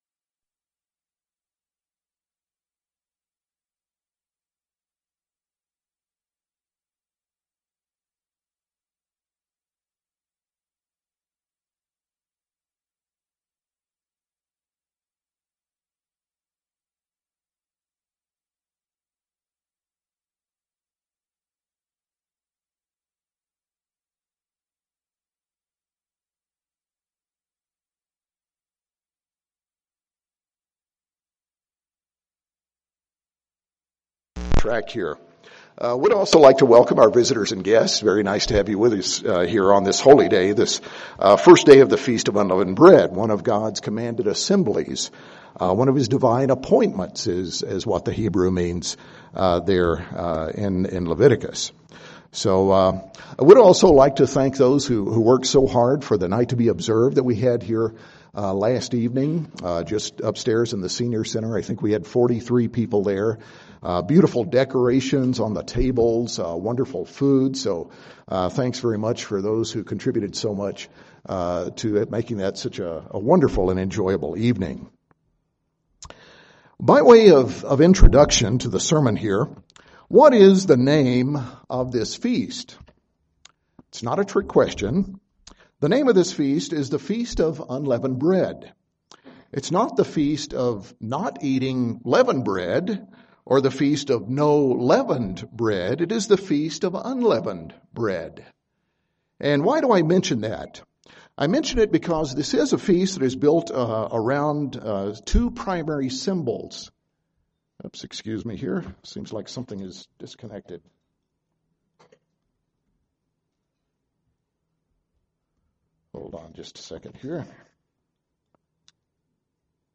On this Holy Day we celebrate the Feast of Unleavened Bread, a Feast built around two symbols—leaven and leavened bread, which God commands us to avoid, and unleavened bread, which He commands us to eat.
In this sermon we'll examine the primary and profound symbolism of the unleavened bread we are to eat during this Feast.